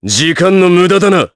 Riheet-Vox_Skill3_jp_b.wav